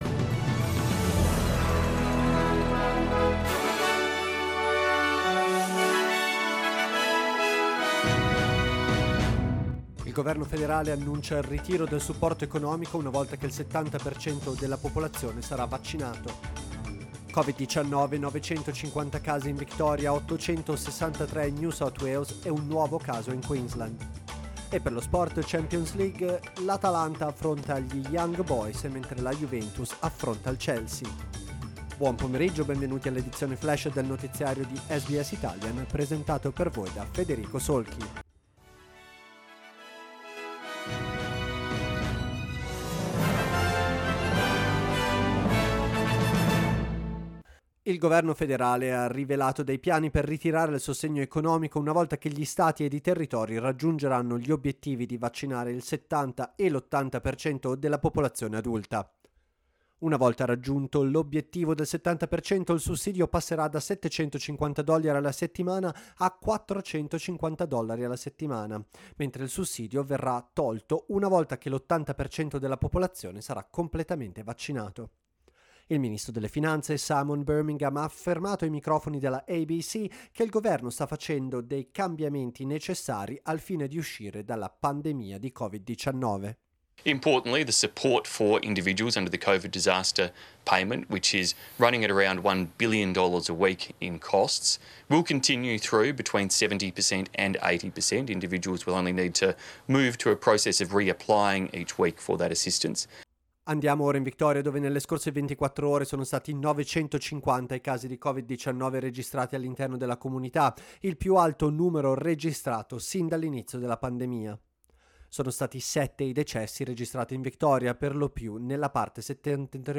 L'aggiornamento delle notizie di SBS Italian.